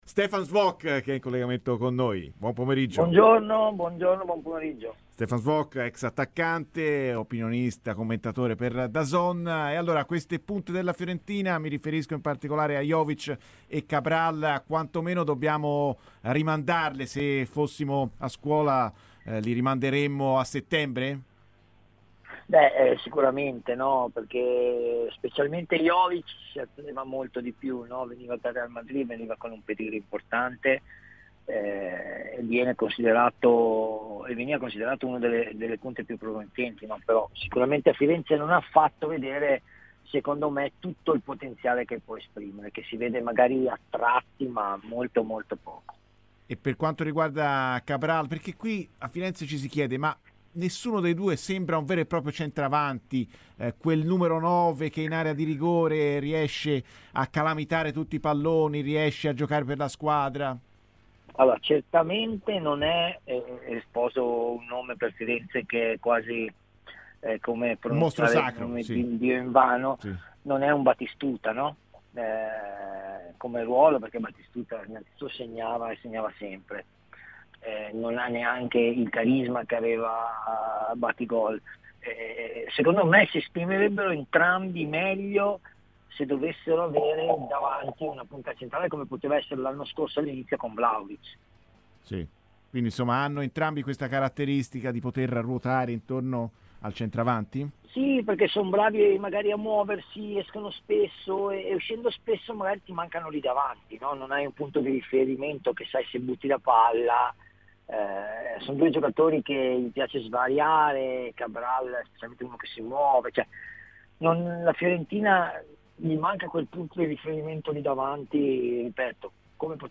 Stefan Schwoch, ex attaccante, ha parlato da Radio FirenzeViola dei temi più attuali in casa Fiorentina: "Cabral non è un batistuta come ruolo, non ha neanche il suo carisma. Sia lui che Jovic renderebbero meglio se avessero davanti una punta come poteva essere Vlahovic. Perché sono bravi a muoversi, gli piace variare. Manca un perno là davanti, insomma".